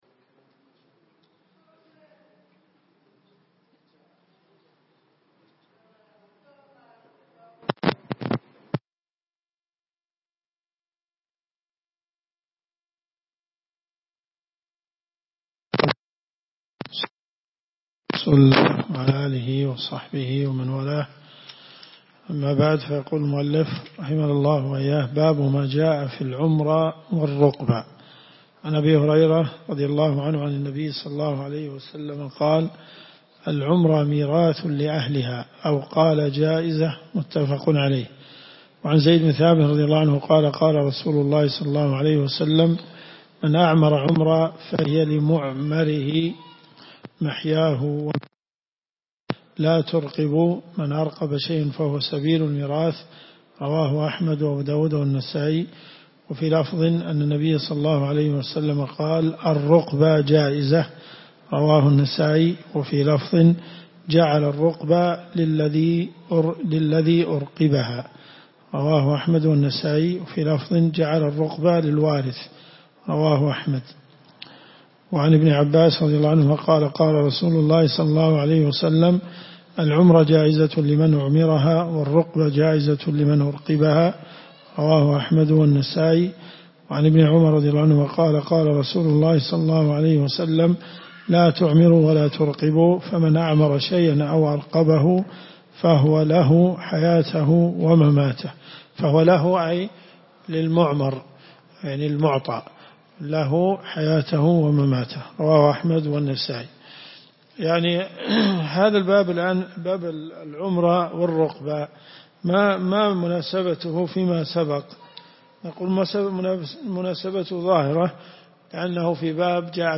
الرئيسية الكتب المسموعة [ قسم أحاديث في الفقه ] > المنتقى من أخبار المصطفى .